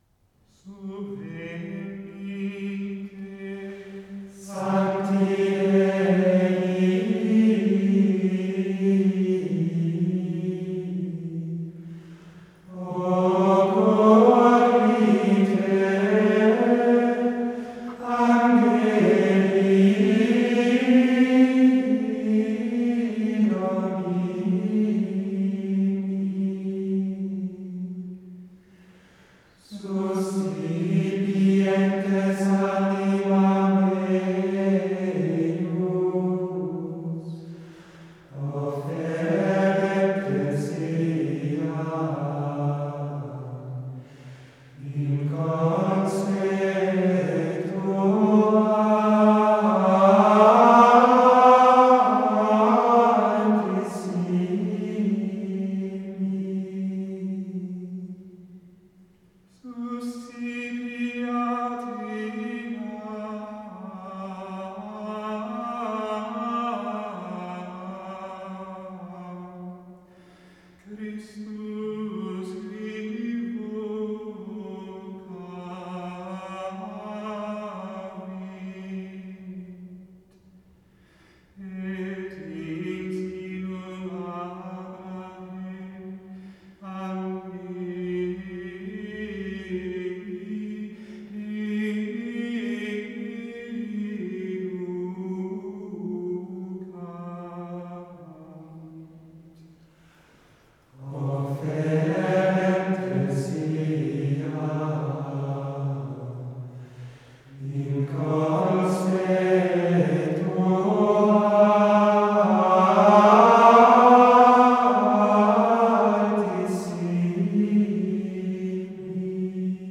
Responsorium Subvenite (Musik)